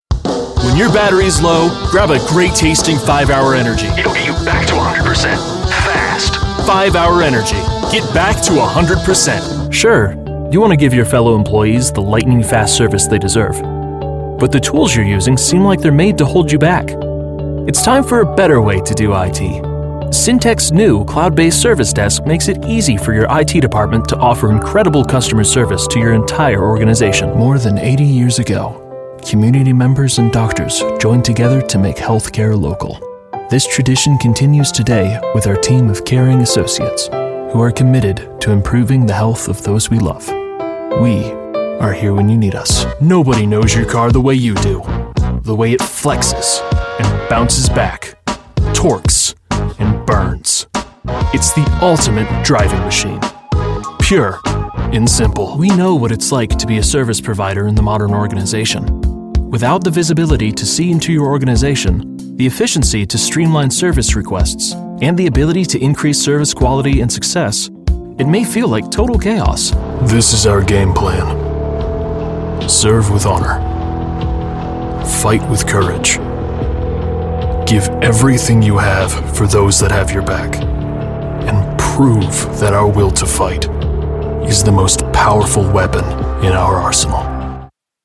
Teenager, Young Adult, Adult, Mature Adult
Has Own Studio
british rp | natural
southern us | natural
standard us | natural
COMMERCIAL 💸